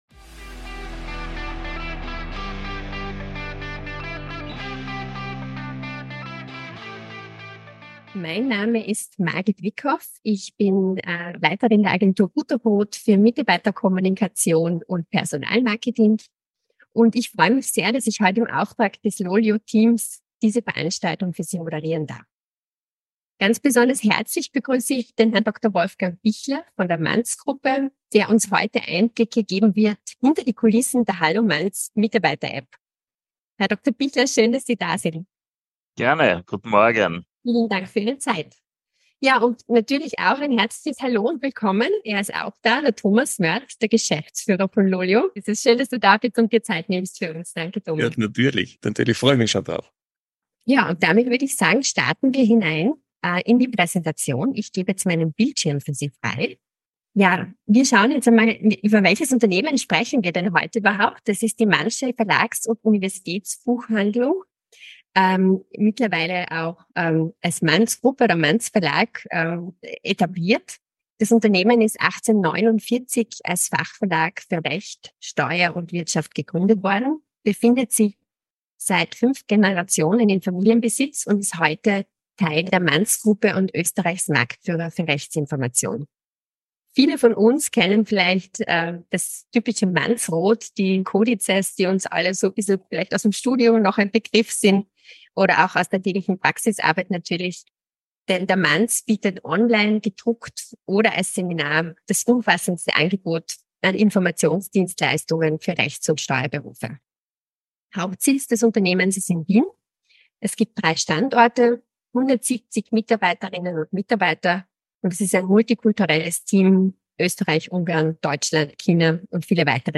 Success Talks by LOLYO ist ein Podcast mit ausgewählten Talkgästen und spannenden Themen rund um die unternehmensinterne Kommunikation und Mitarbeiter-Apps.